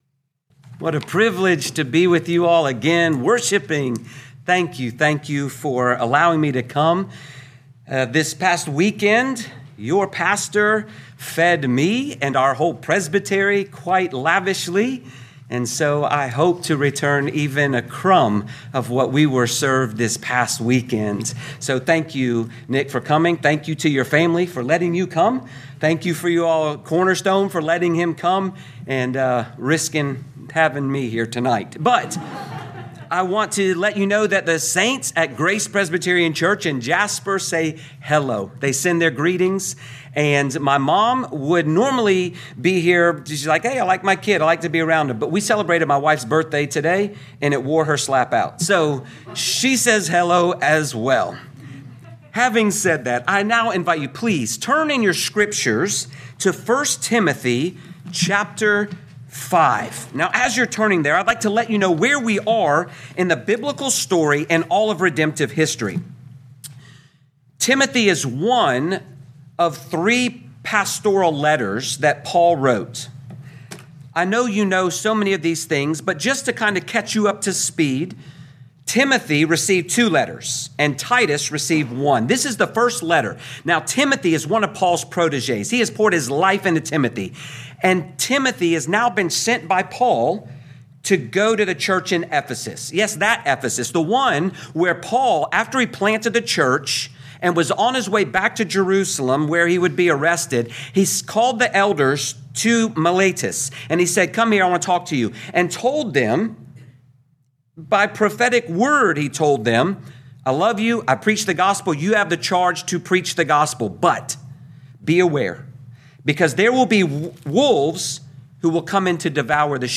2026 1 Timothy Evening Service Download